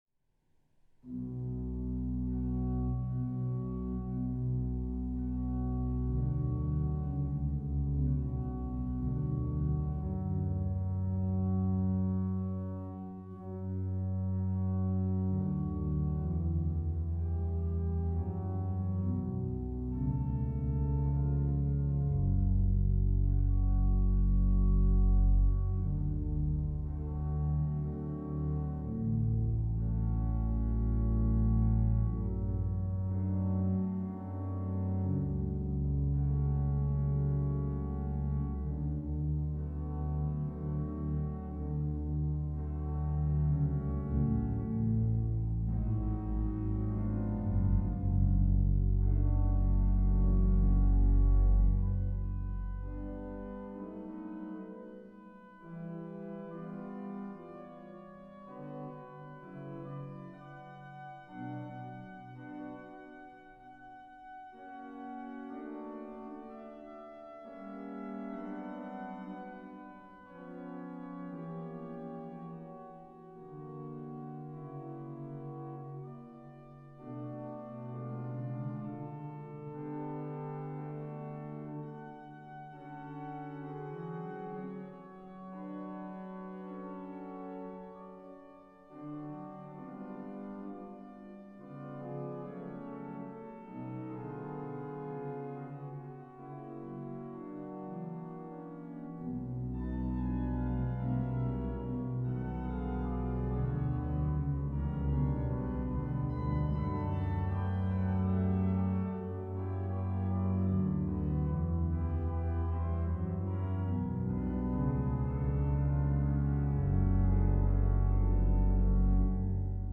Organo romantico storico inglese